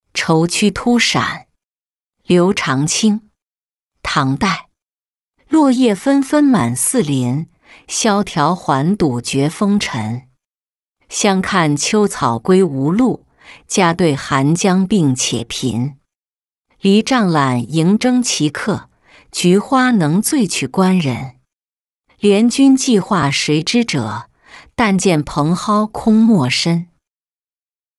酬屈突陕-音频朗读